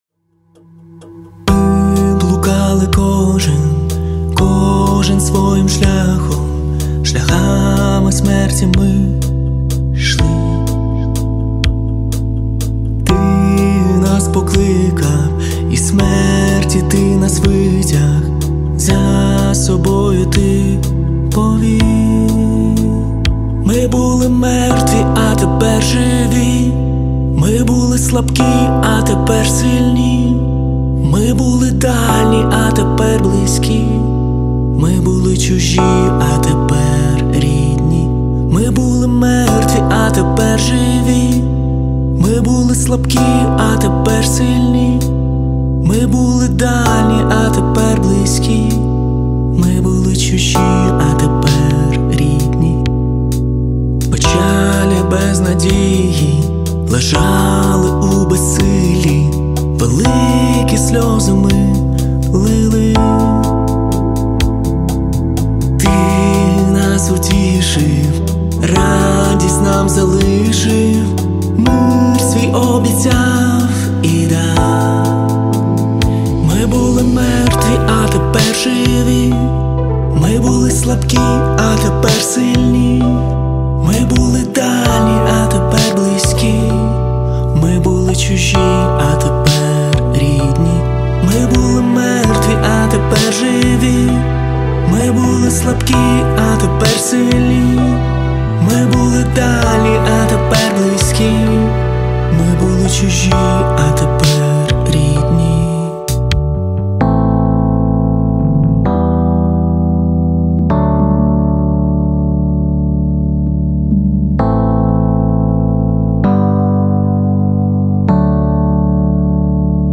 Соло-гітари в кінці: